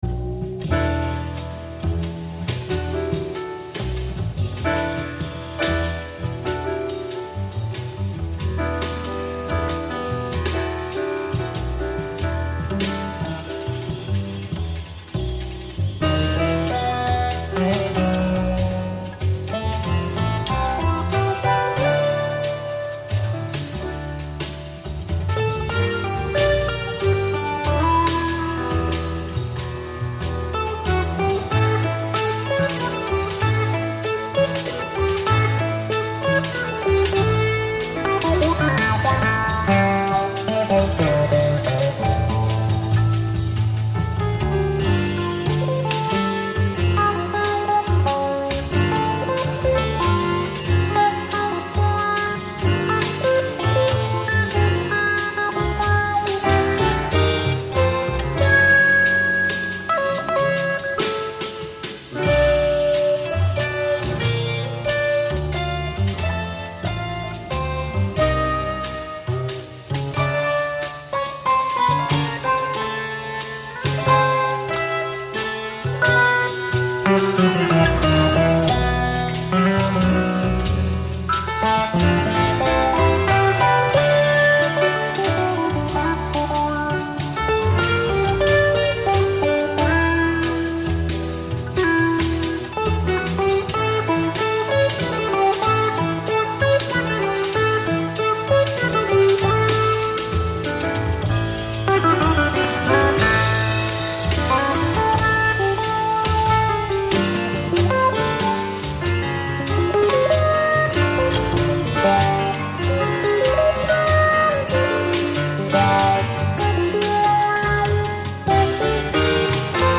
Today’s explorations will lean toward jazz.